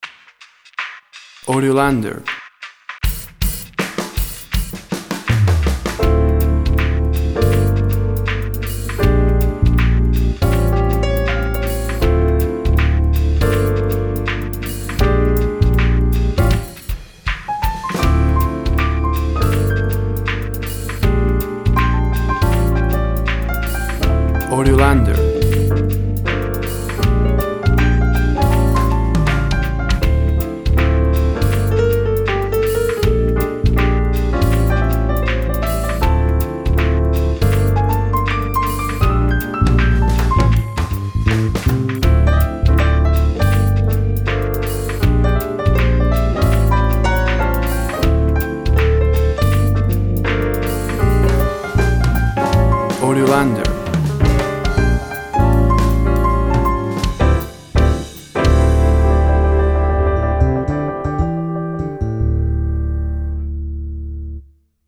Acid Smooth Jazz with urban landscape.
Tempo (BPM) 90